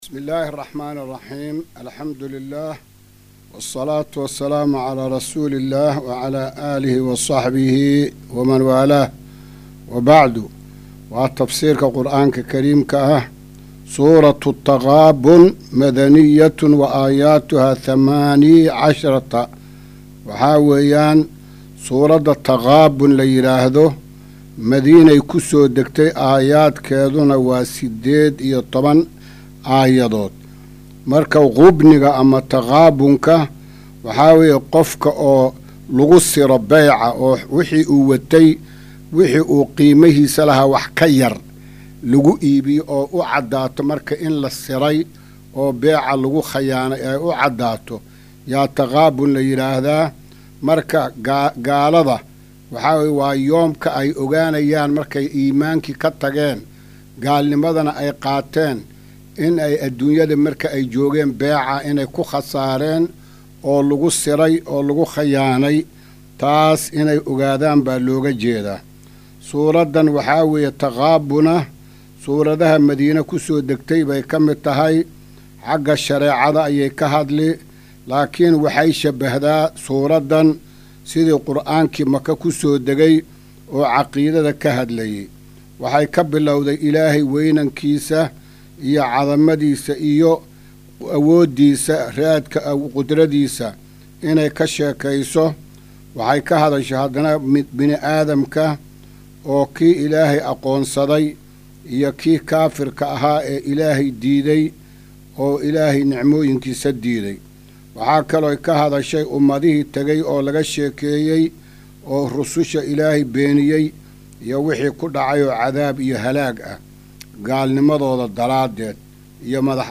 Casharka-265aad-ee-Tafsiirka.mp3